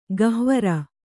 ♪ gahvara